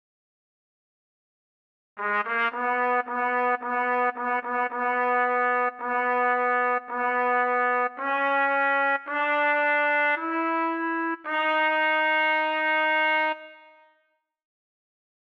Key written in: B Major
Type: Barbershop
Each recording below is single part only.